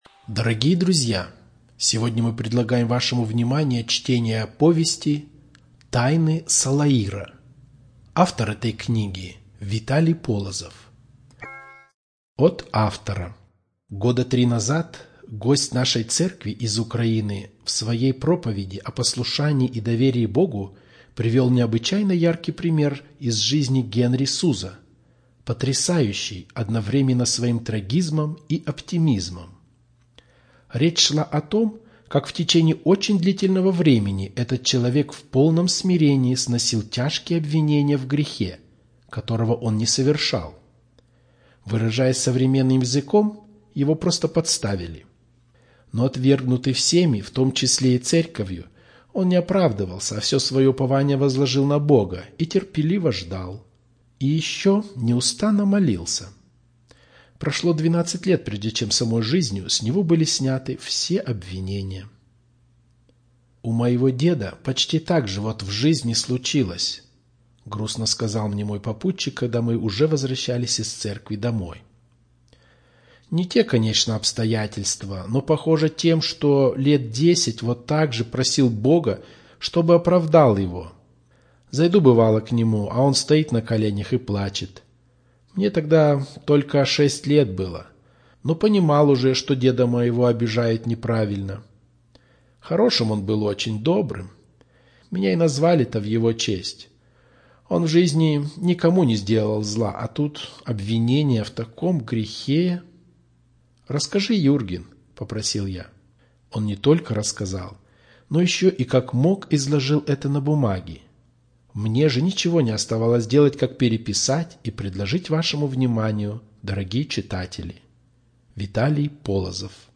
Аудиокнига подготовлена миссией "Свитло на сходи"